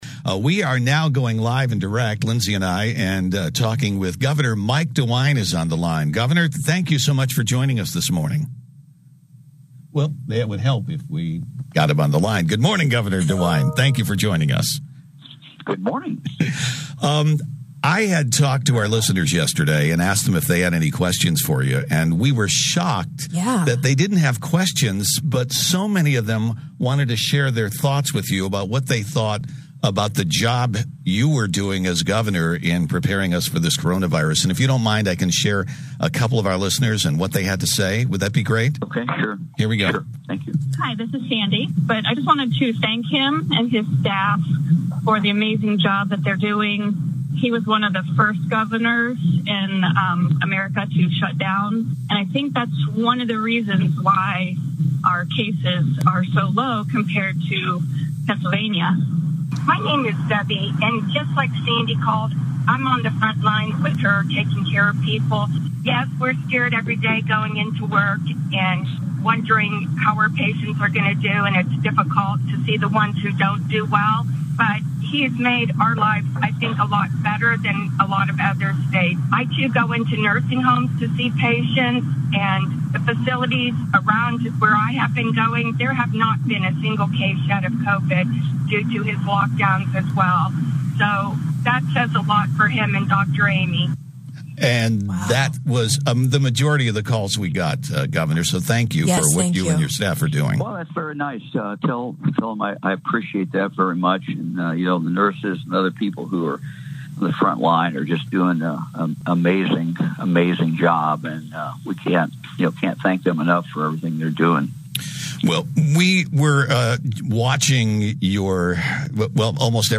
Two Mix 989 listeners, recorded earlier, told the Governor they were pleased how quickly the Governor acted to close the state down to slow down the Covic-19 pandemic spread.